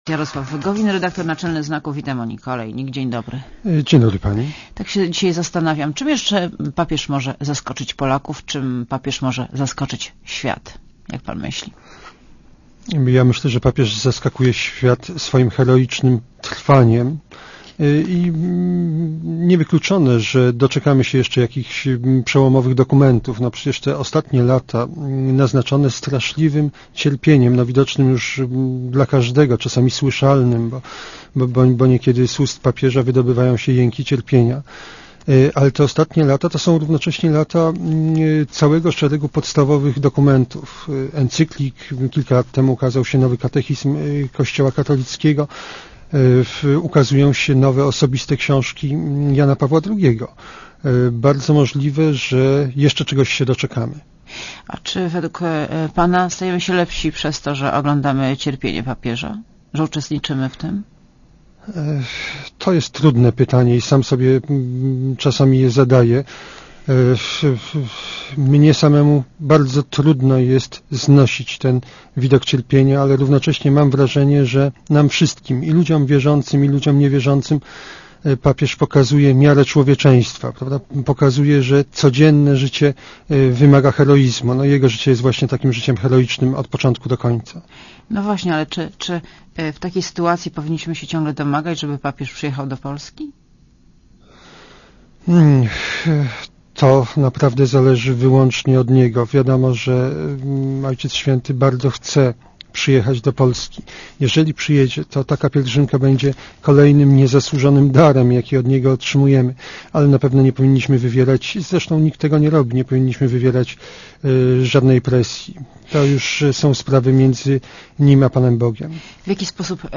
Posłuchaj wywiadu (3,1 MB) A gościem Radia Zet jest Jarosław Gowin, redaktor naczelny miesięcznika „Znak”.